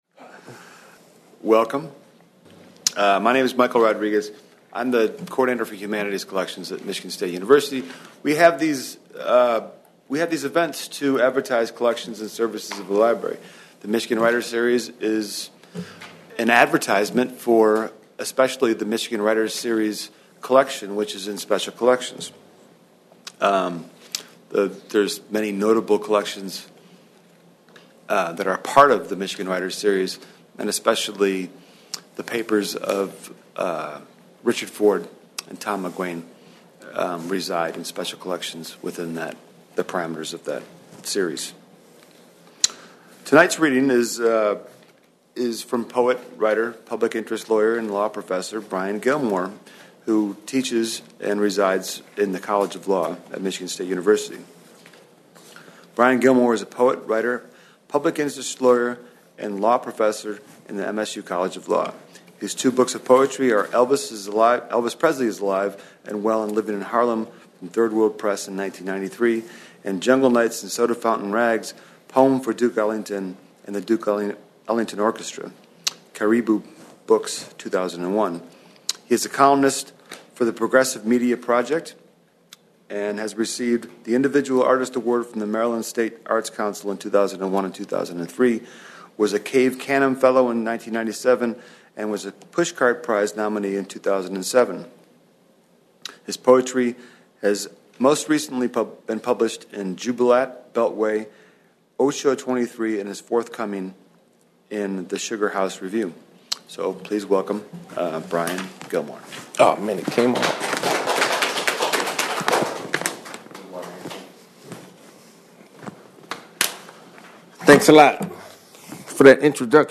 Recorded at MIchigan State University Main Library